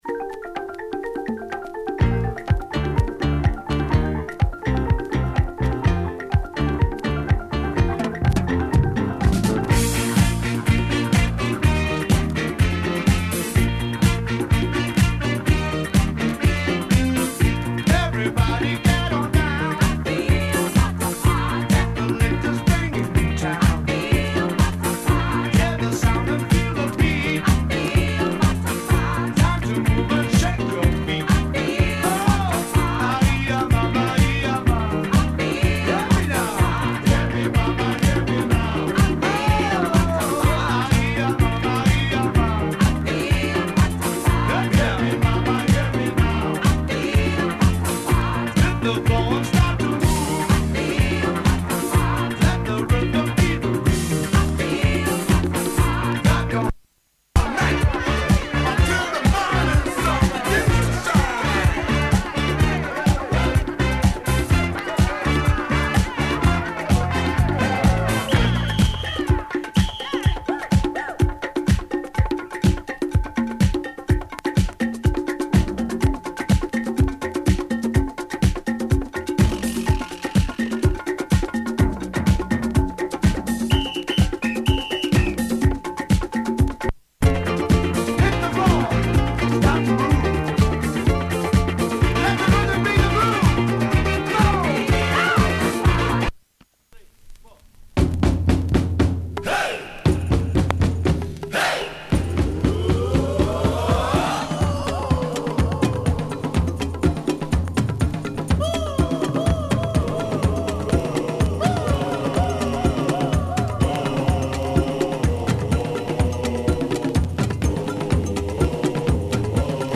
jungle percussive shakedown kind of thing